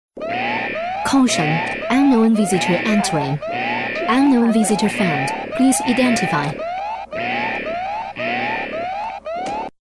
女150-广播提示【警示-英文】
女150-中英双语 成熟知性
女150-广播提示【警示-英文】.mp3